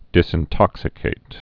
(dĭsĭn-tŏksĭ-kāt)